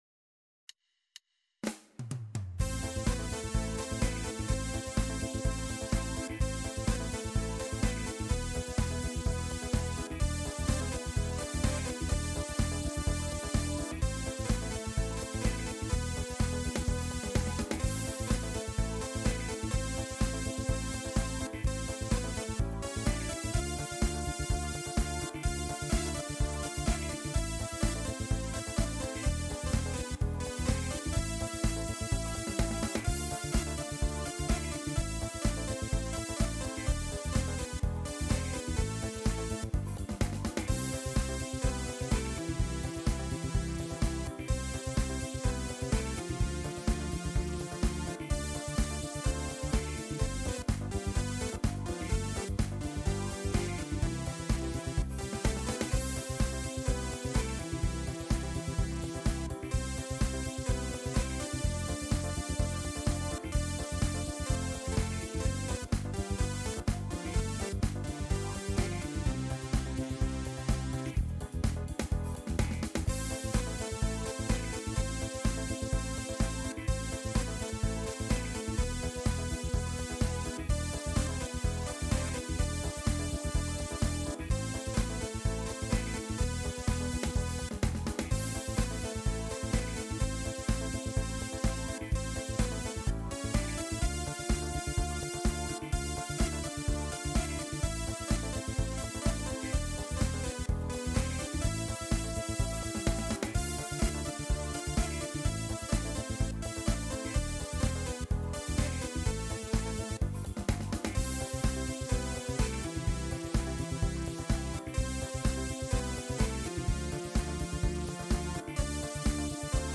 Disco-Fox